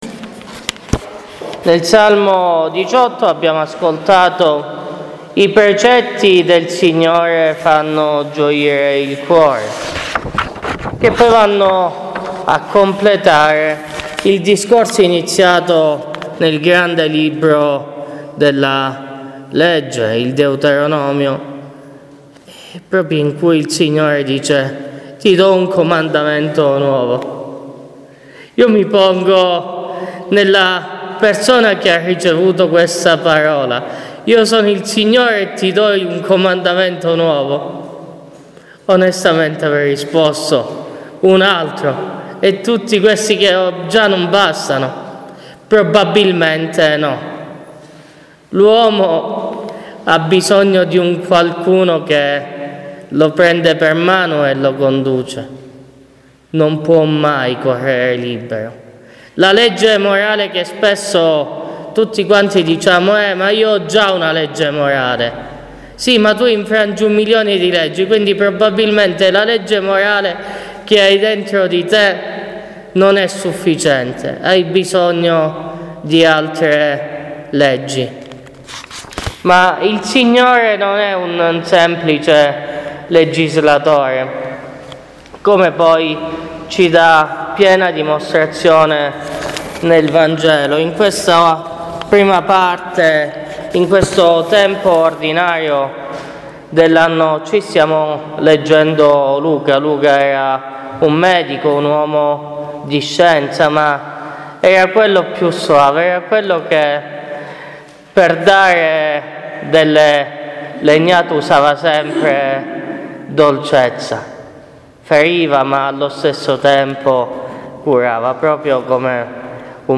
Omelia della XV domenica del Tempo Ordinario